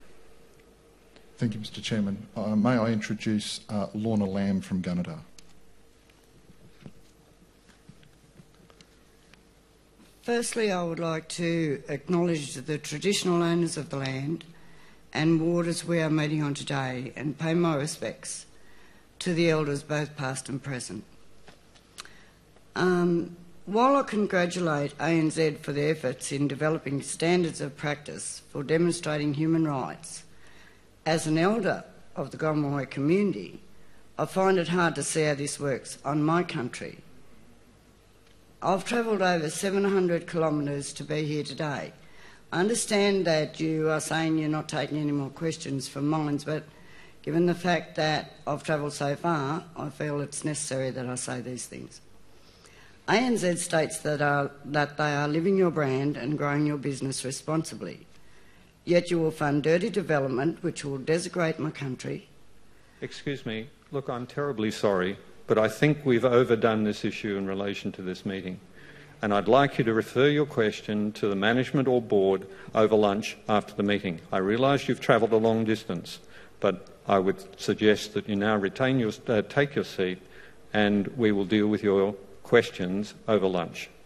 Fossil fuels were top of the agenda at ANZ’s annual general meeting today